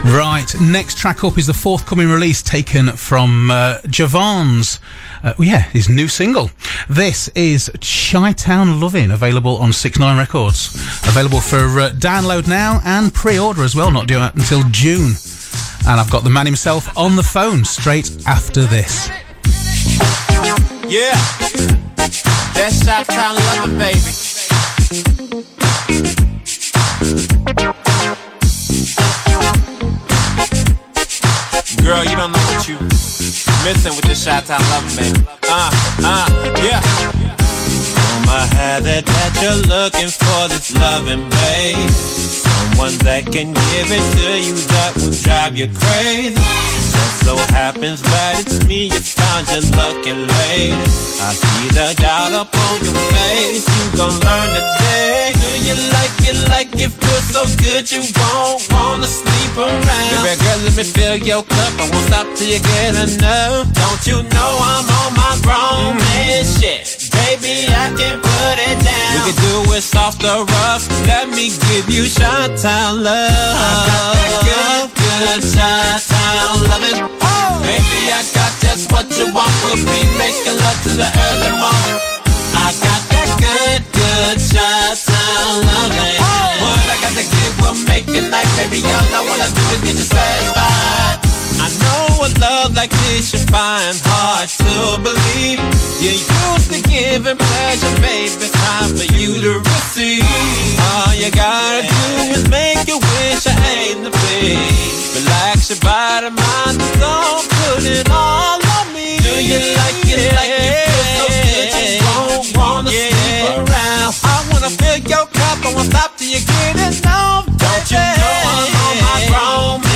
Click the link below to hear the interview from 7th May 2018